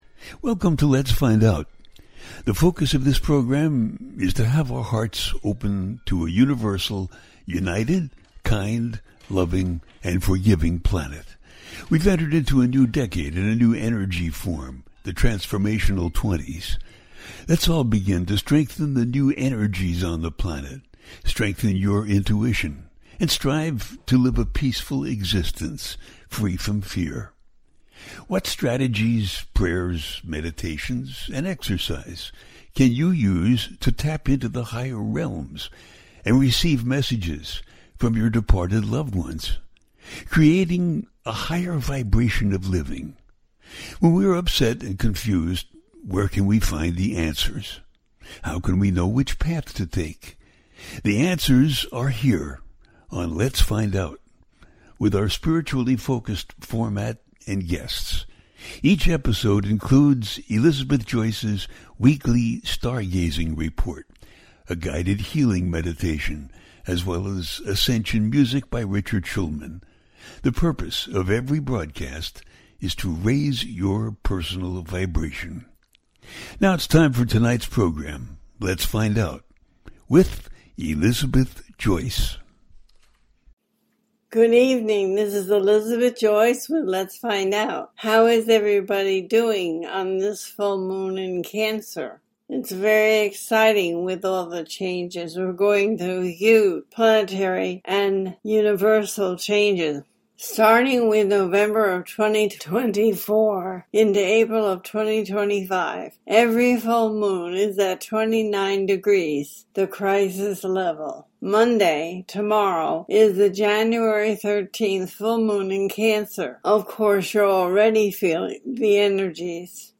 Oh What Now? Whats Happening At The Cancer Full Moon - A teaching show